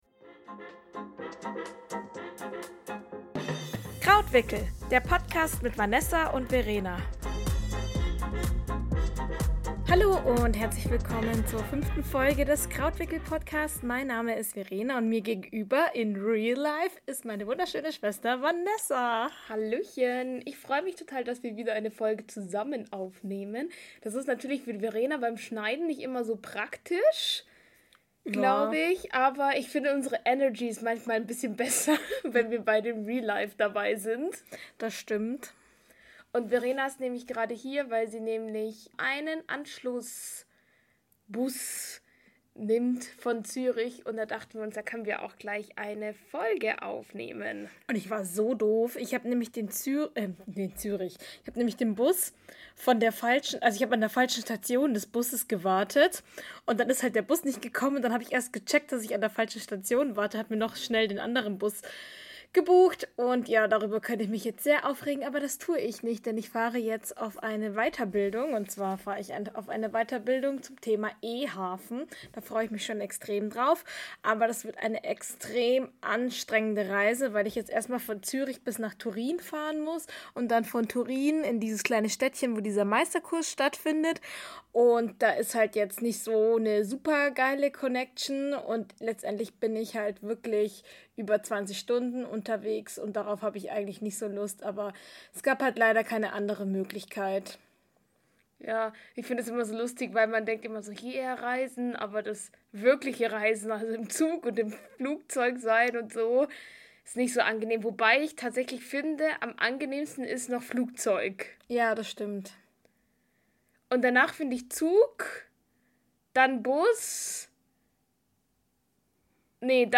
Diese wundervolle Folge wurde wieder einmal zusammen aufgenommen. Neben dem Thema Stottern, sprechen wir über einen schlimmen Zeitungsartikel und über das Jetset-Lied von Carmen Geiss.